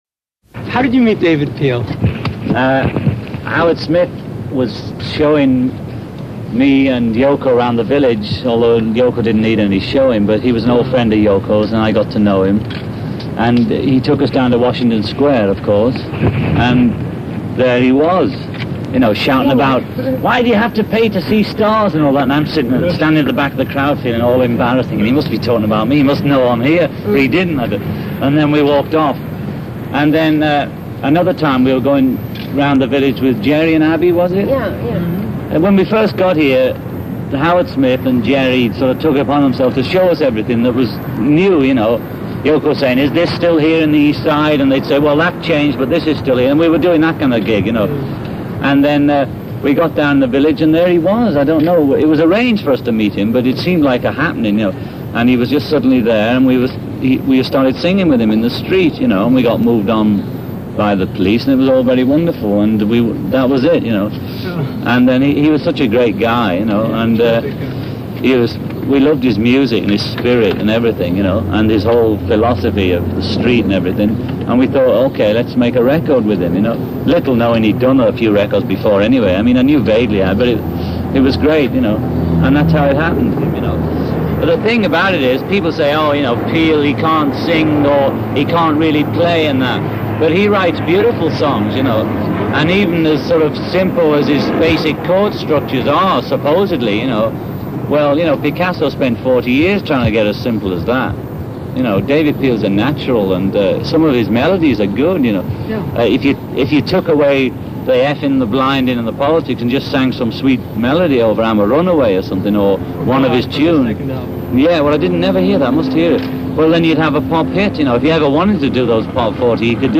John-Lennon-Meeting-David-Peel-Interview-1972.mp3